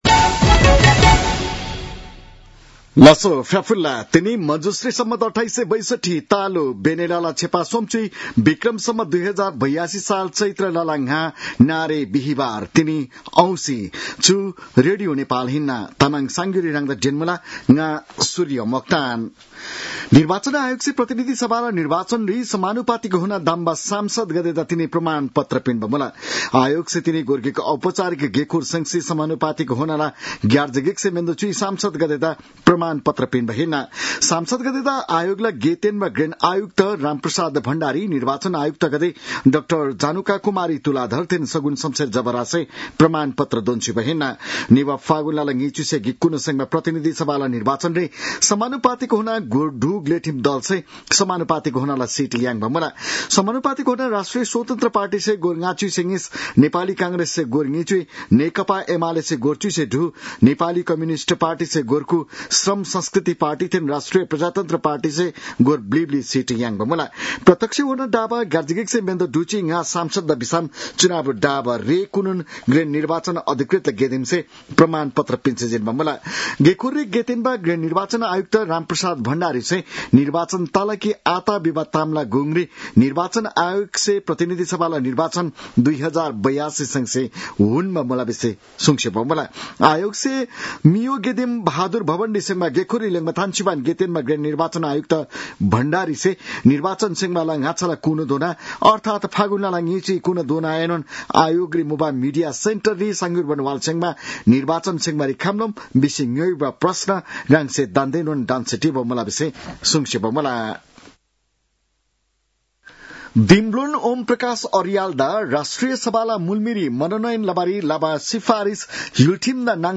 तामाङ भाषाको समाचार : ५ चैत , २०८२
Tamang-news-12-05.mp3